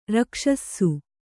♪ rakṣassu